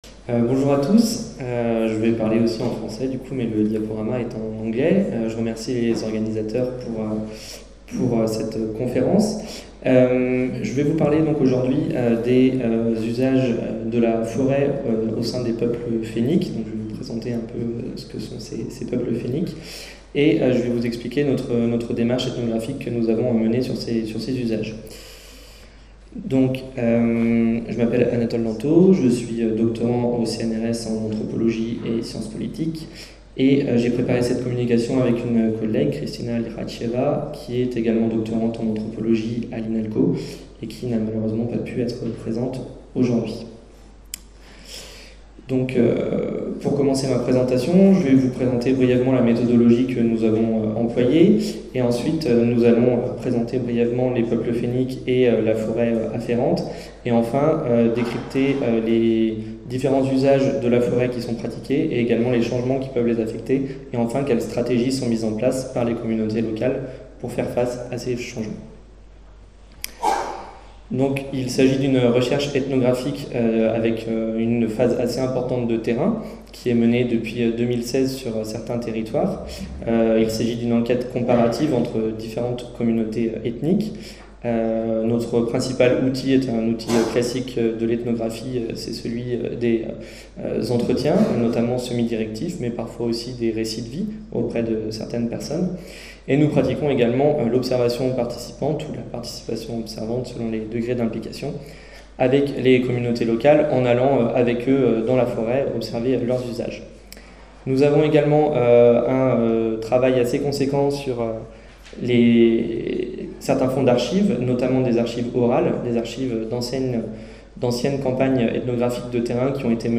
Ce colloque international, qui s'est tenu au sein du Domaine national de Chambord (26 et 27 mars 2019), s'inscrit dans le cadre du projet de recherche COSTAUD (Contribution des OnguléS au foncTionnement de l’écosystème et AUx services rendus à ChamborD, financé par la Région Centre-Val de Loire et porté par l'Irstea, 2016-2019).